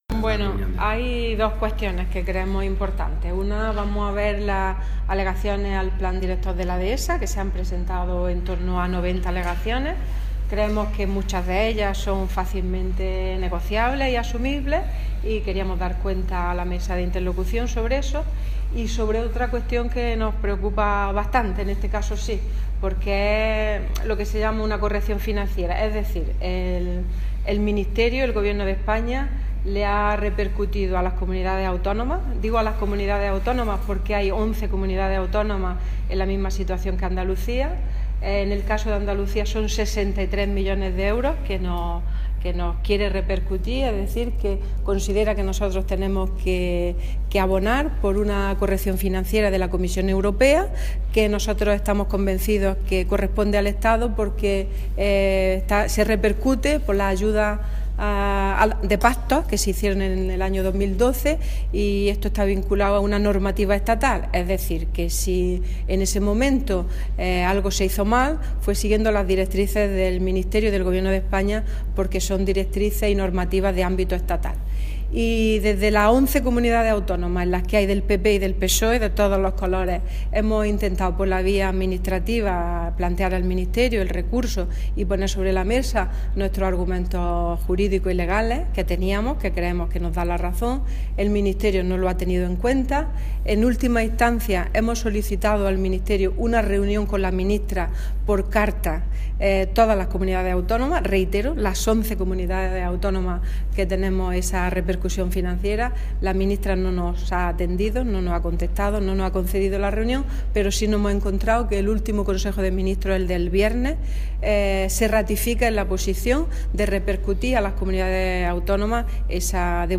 Declaraciones consejera Mesa Interlocución